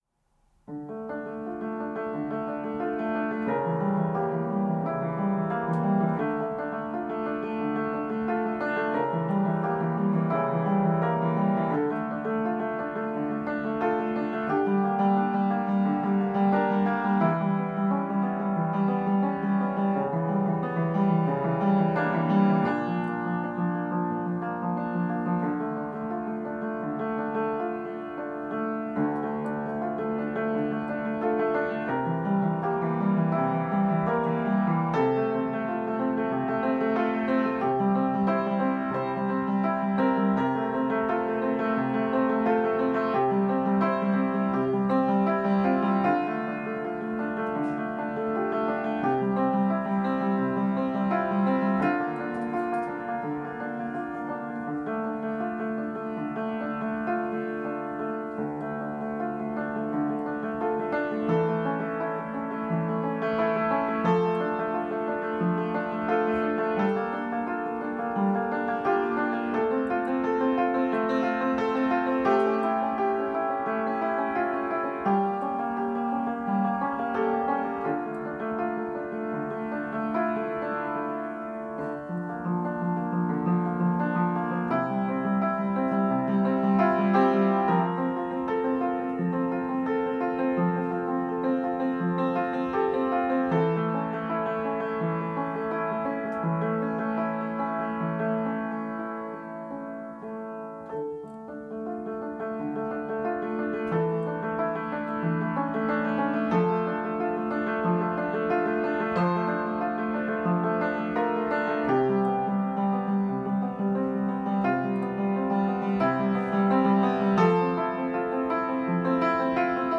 piano solos
Piano